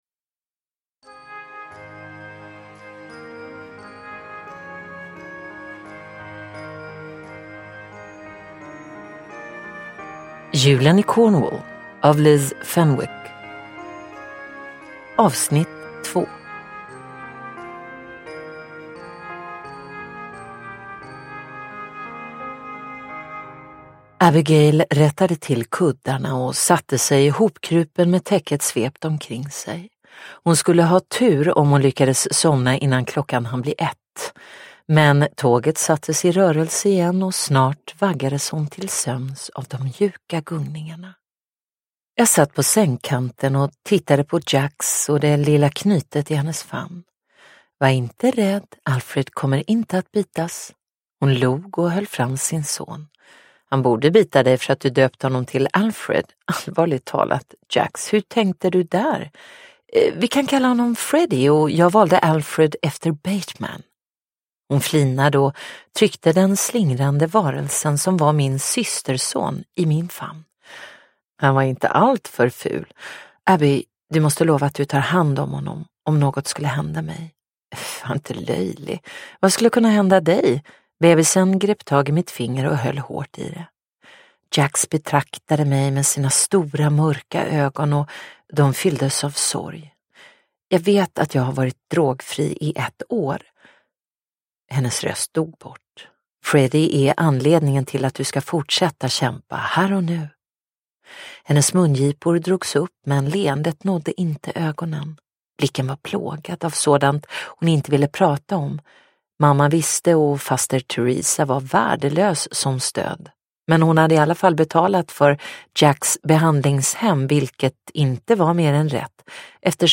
Julen i Cornwall - Del 2 : En julsaga – Ljudbok – Laddas ner